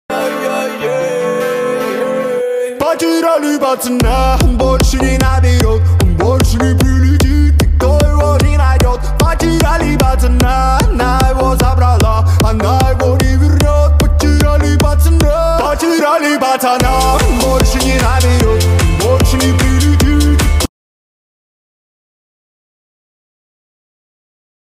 • Качество: 128, Stereo
мужской голос